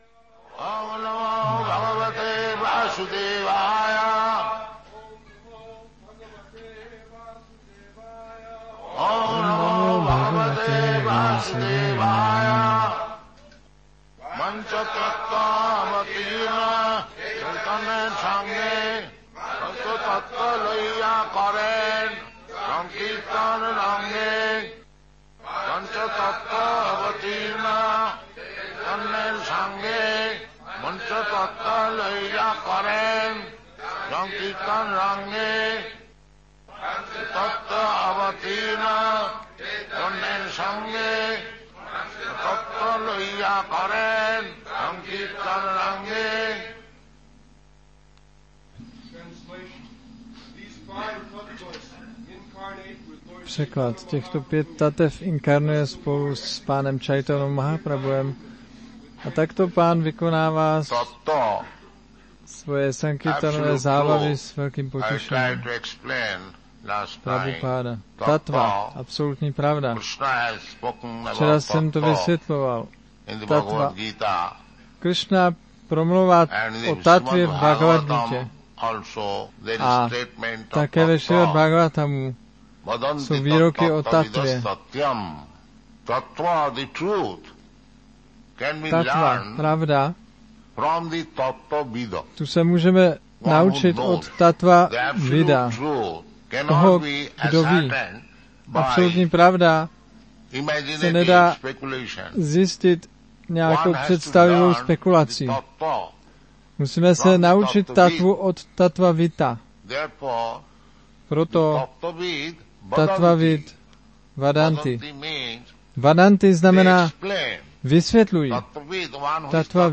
1974-03-04-ACPP Šríla Prabhupáda – Přednáška CC-ADI-7.4 Mayapur